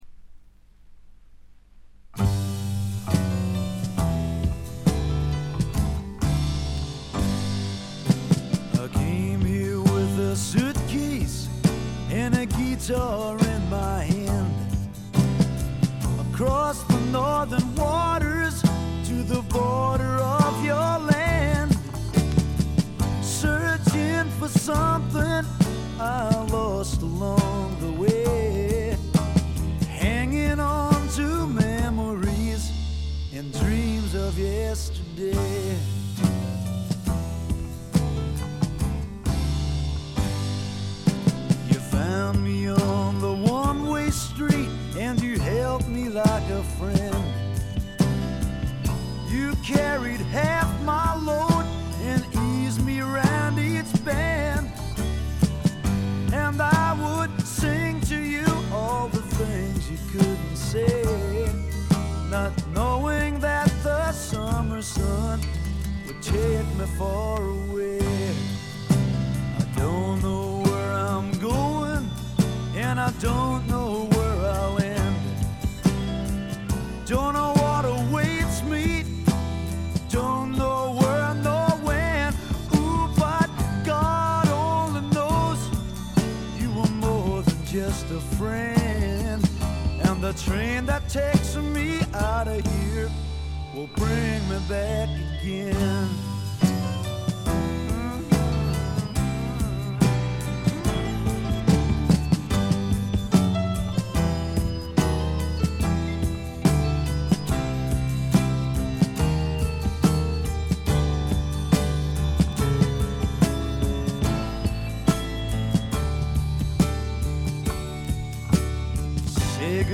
静音部でチリプチ。散発的なプツ音少し。
なにはともあれ哀愁の英国スワンプ／英国フォークロック基本中の基本です。
試聴曲は現品からの取り込み音源です。